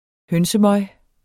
Udtale [ ˈhœnsəˌmʌj ] Betydninger afføring fra høns